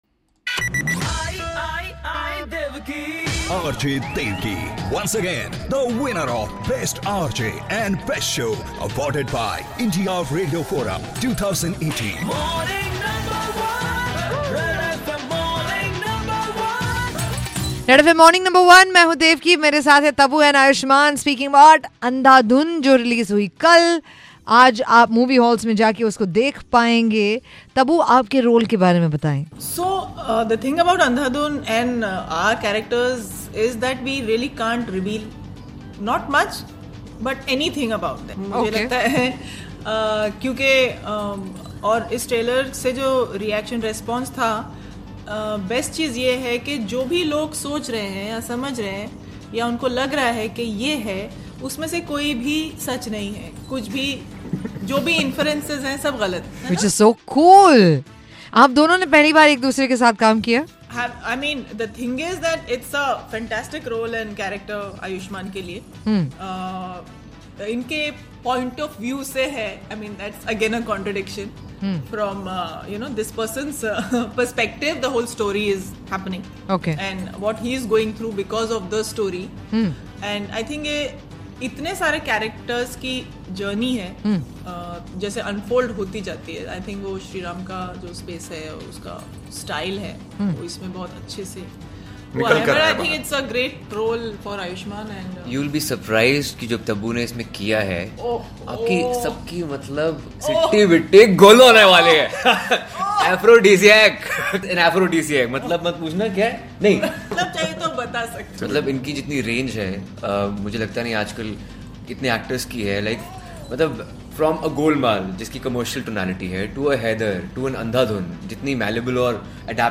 10.2- Interview with cast of Andhadhun- 2